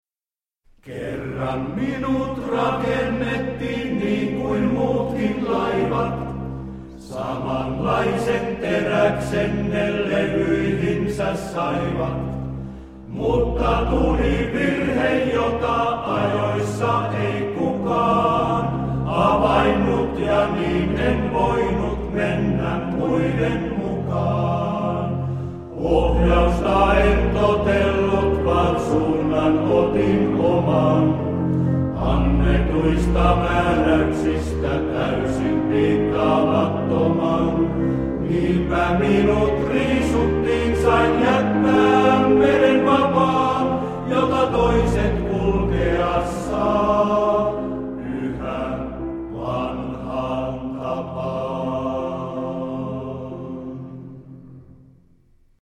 kontrabasso
piano